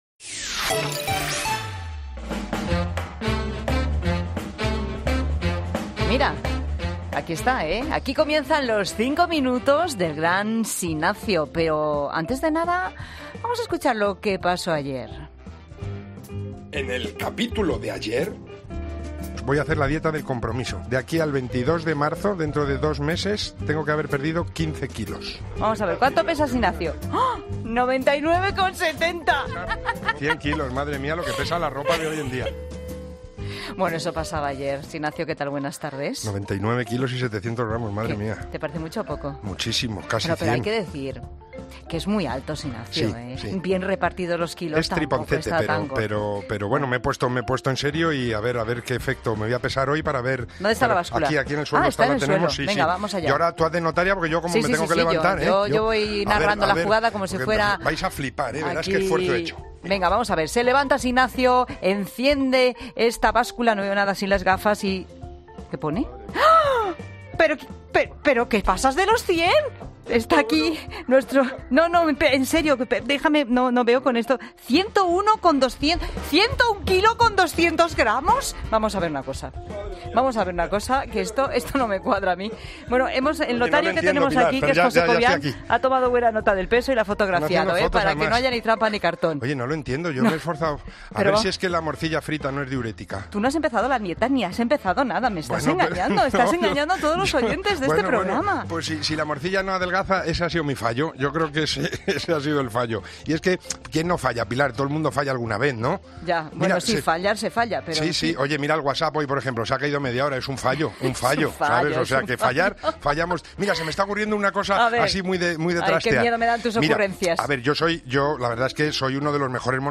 Como a diario, los cinco minutos terminan con la estridente bocina que indica el final de la sección.